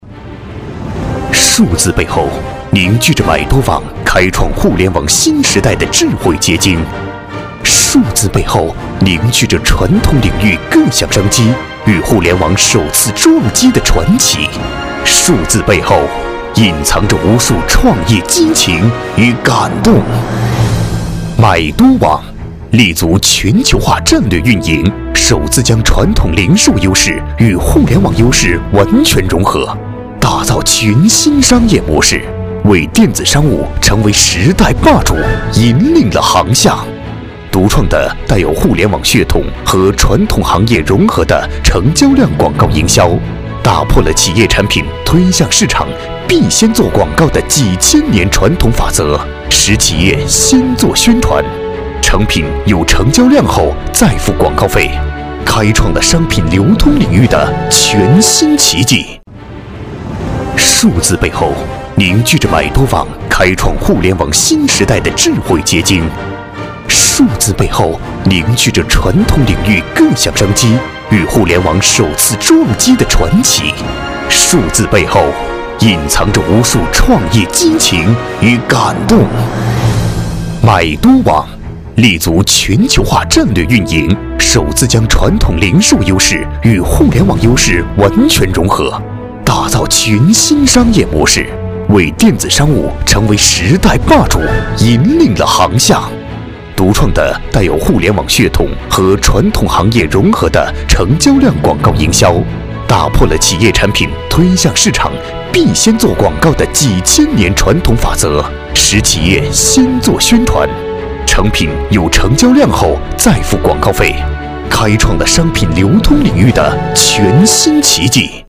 • 男S337 国语 男声 专题片-买多网-大气、稳重 大气浑厚磁性|沉稳